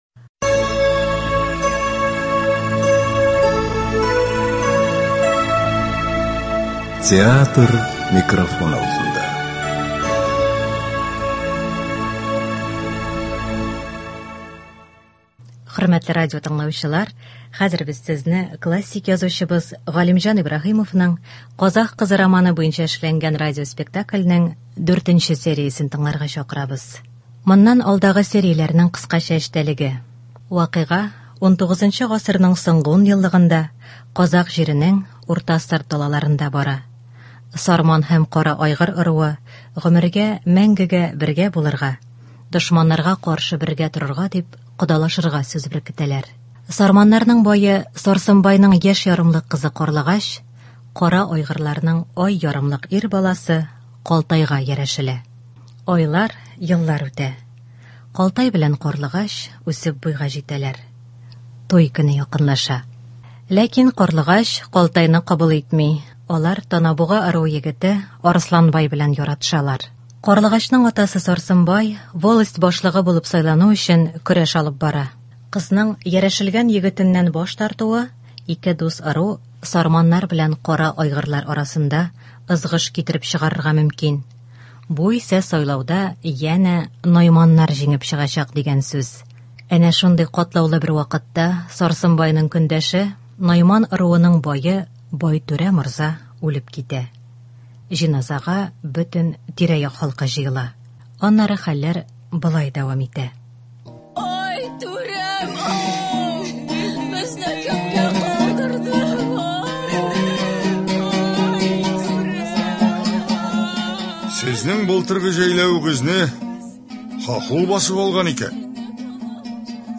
Радиоспектакль.